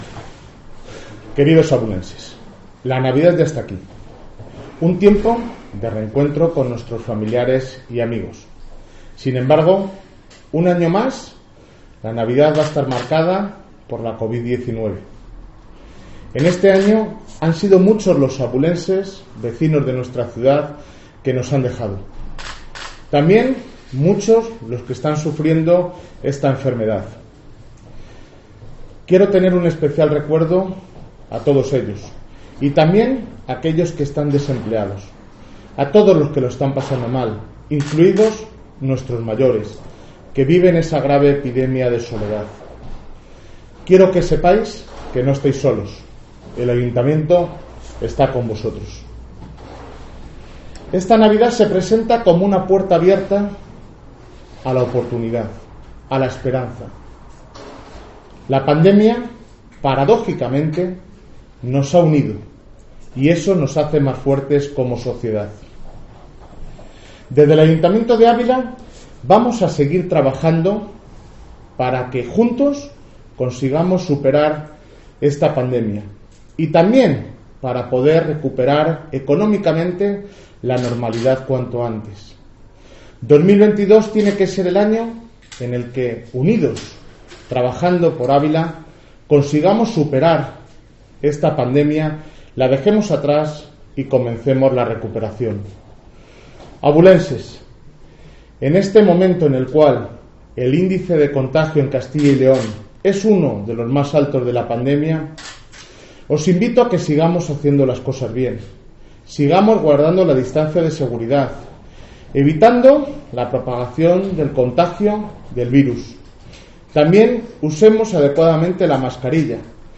Discurso navideño del alcalde de Ávila